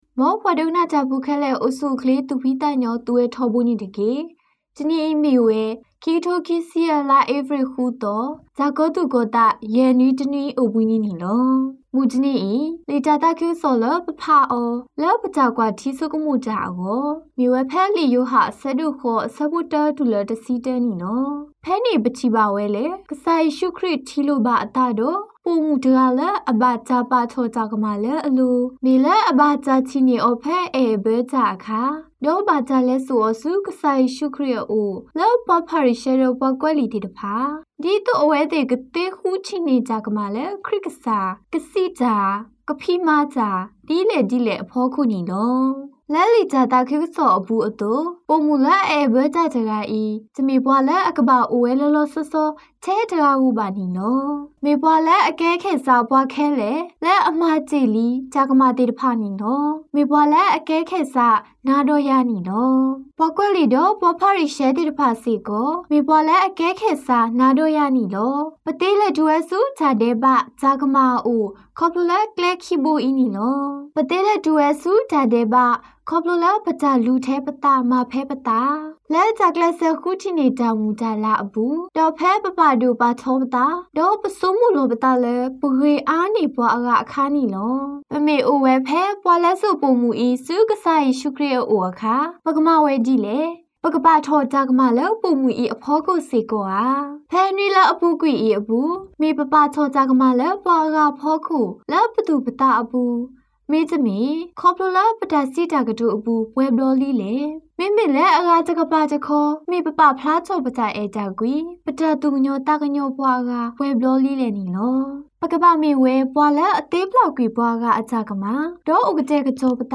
Homily for the 5th Sunday of Lent April 6 2025